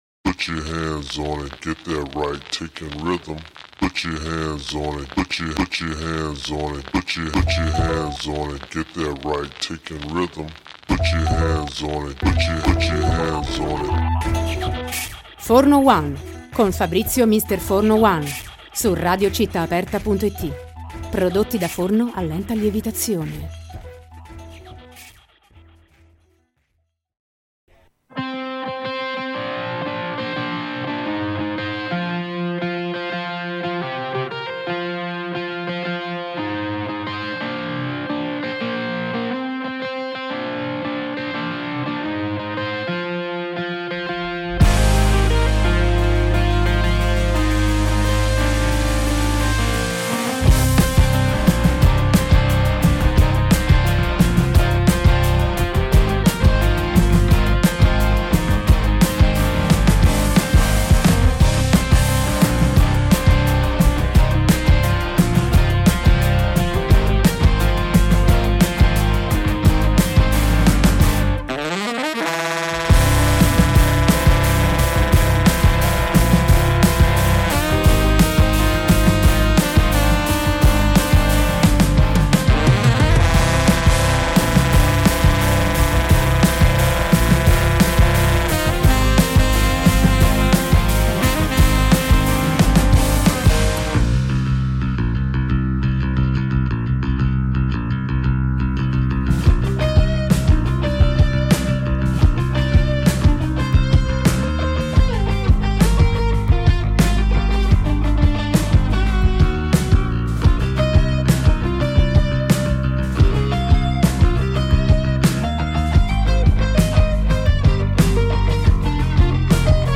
Intervista-Treetops-Aphonia.mp3